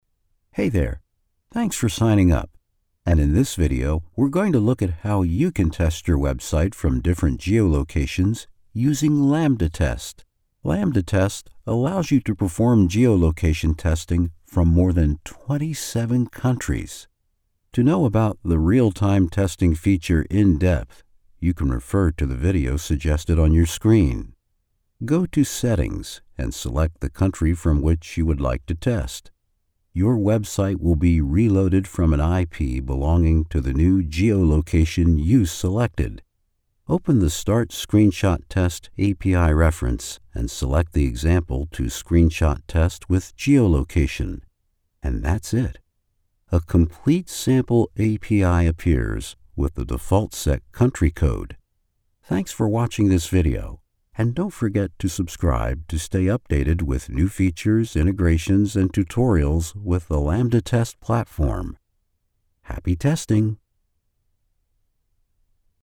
E-Learning
iMac, Neumann TLM 103-Mikrofon, Steinberg UR 22-Schnittstelle, Adobe Audition.
4' x 6' x 7' Studio-Innenraum. Durchgehend mit Teppichboden ausgelegt. Doppelt verkleidet. Durchschnittlicher Geräuschpegel -55 dB.
Im mittleren Alter
Senior